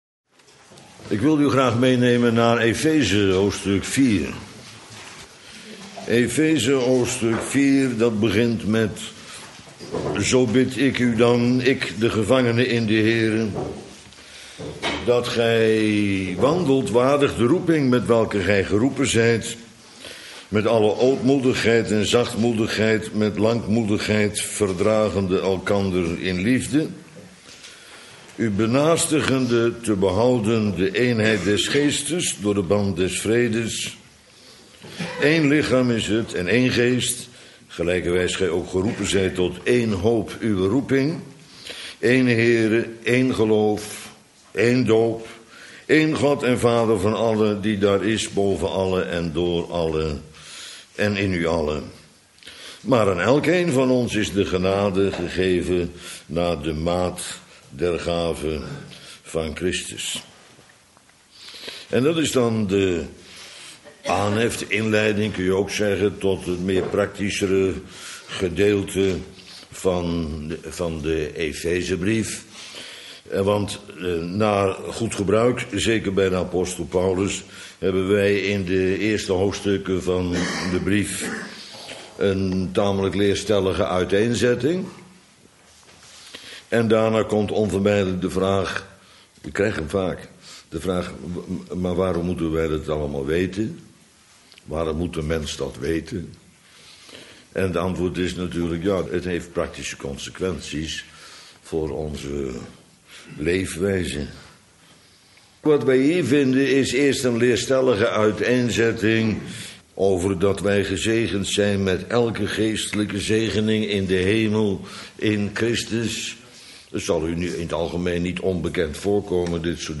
Uitverkoren - Bijbels Panorama bijbellezing